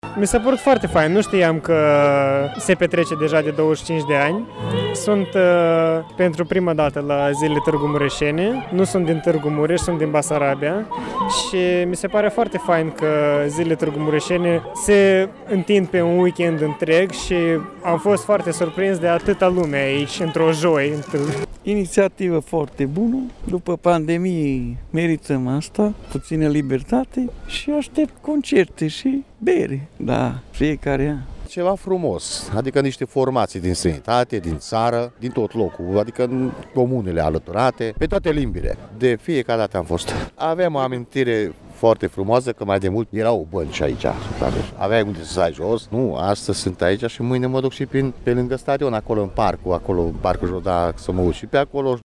Atât târgumureșenii cât și turiștii sunt încântați de revenirea sărbătorii orașului: